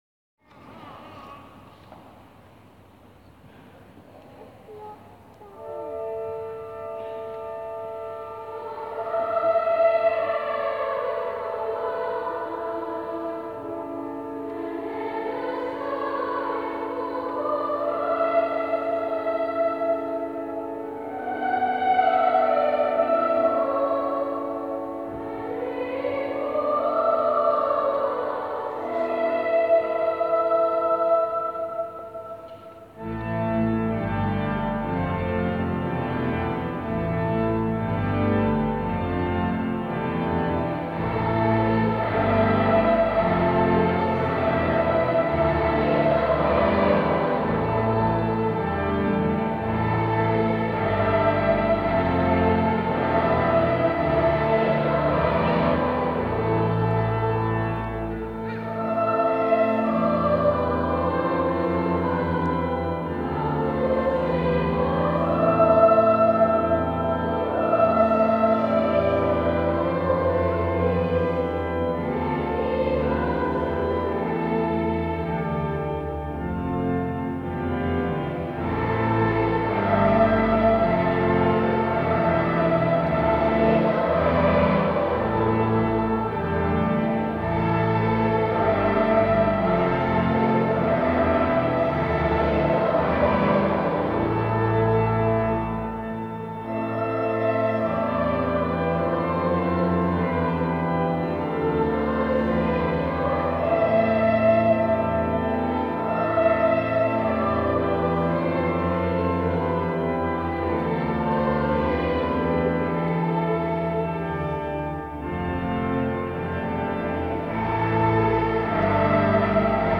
in their new school choir
in Salisbury Cathedral as part of the school's carol concert on 14 December 2016